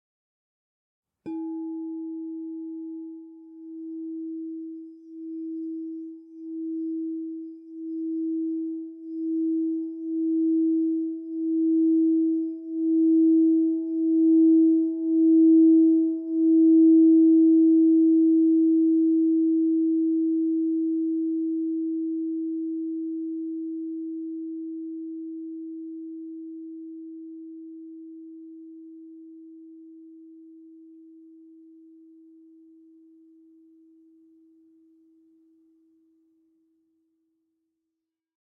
Meinl Sonic Energy 10" Marble Crystal Singing Bowl E4, Yellow, 432 Hz, Solarplexus Chakra (MCSB10E)
The Meinl Sonic Energy Marble Crystal Singing Bowls made of high-purity quartz create a very pleasant aura with their sound and design.